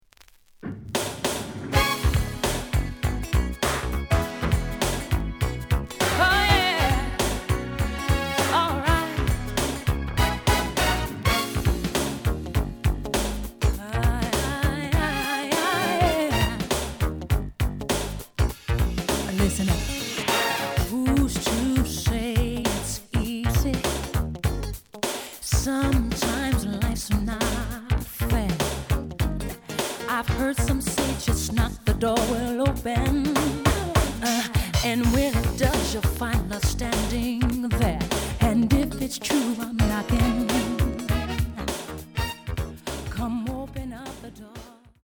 The audio sample is recorded from the actual item.
●Genre: Funk, 80's / 90's Funk
Slight edge warp.